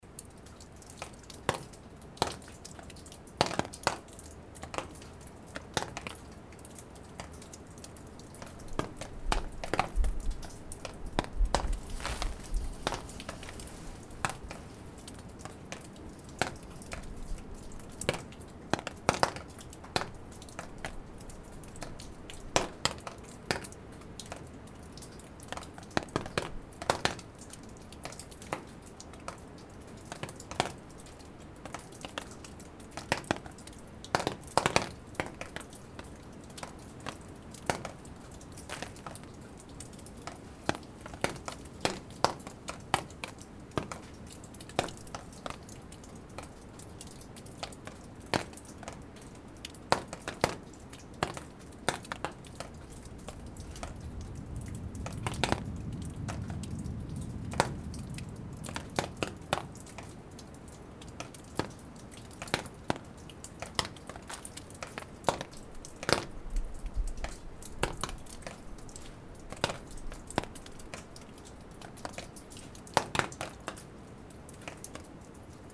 Schwere Wassertropfen fallen von der Decke ins Laub.
Die ganze Nacht trommeln die Tropfen auf die Plane im Sekundentakt.